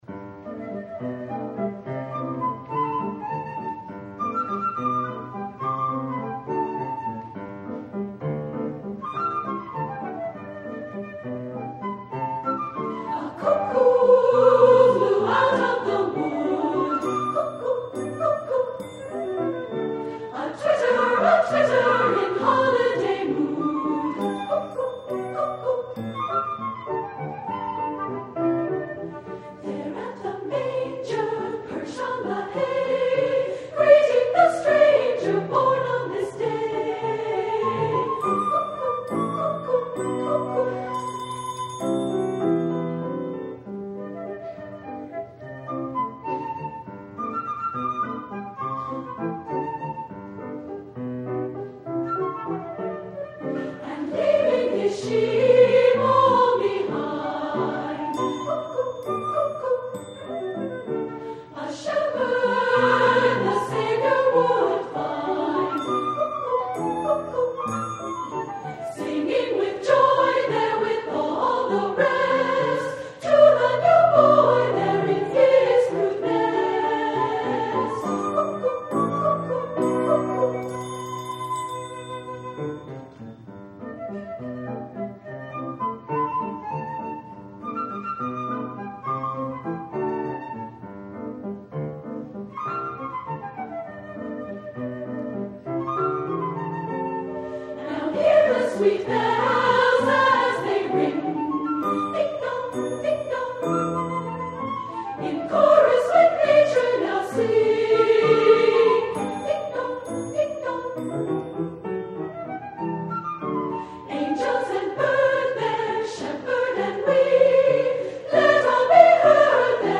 Voicing: Unison and Piano